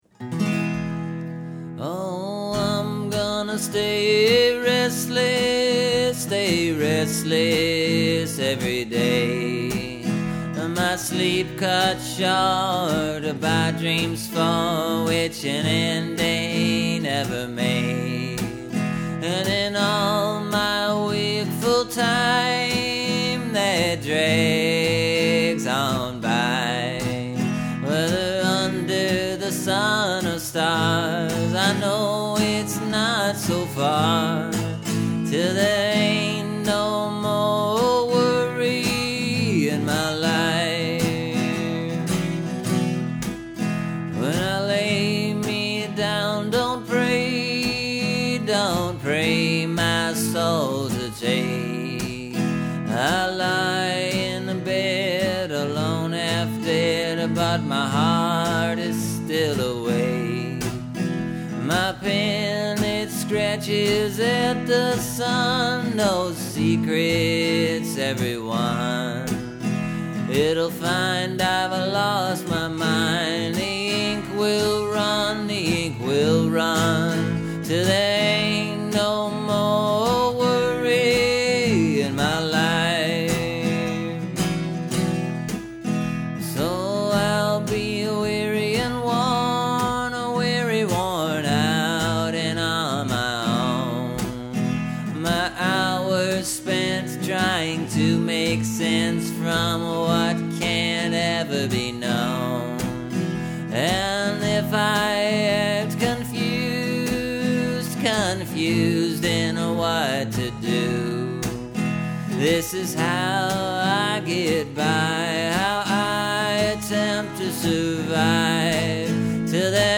You can see, if you compare this to the one I released Monday (listen here), that this is slower. Well, not, necessarily the tempo. But something about it is slower.